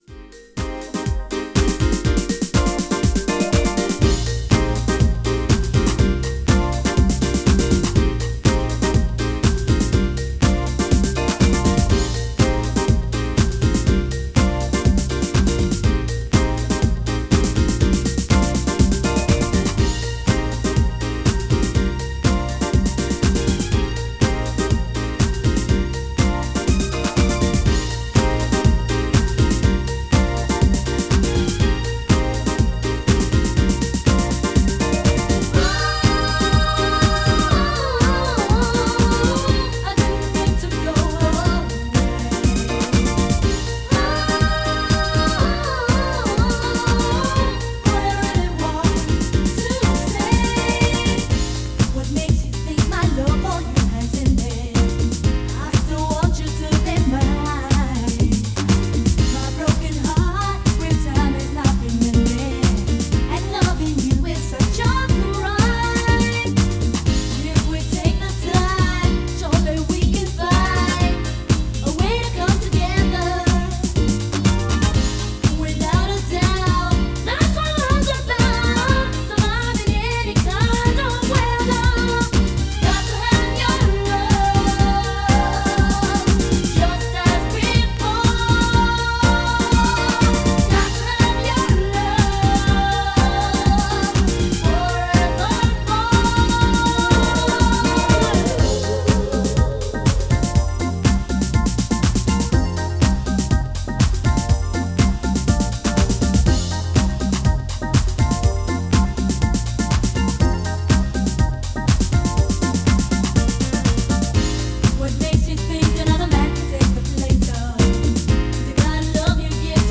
STYLE House